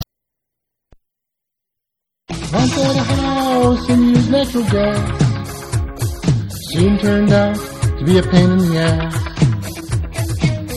Rock and Roll classics